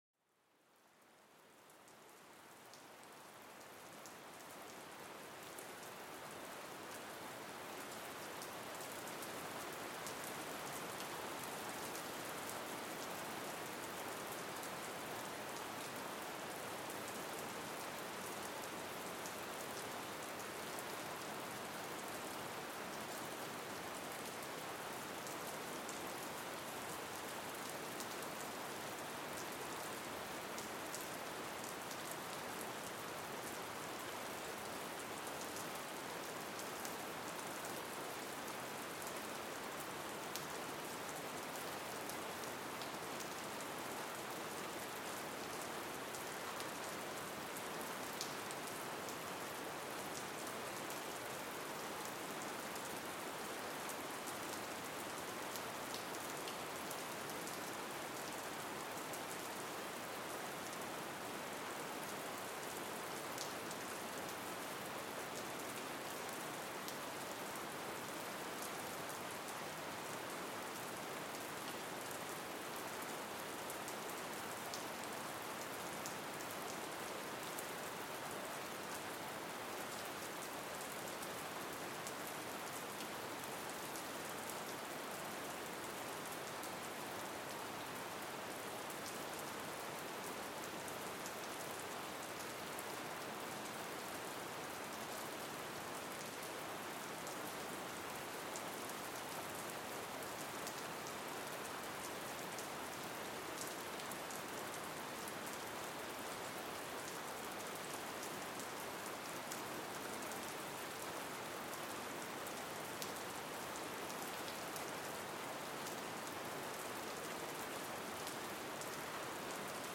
Lluvia calmante: Relájate y mejora tu sueño
En este episodio, déjate mecer por el suave sonido de una delicada lluvia. Este sonido natural te envolverá en calma y reducirá el estrés acumulado.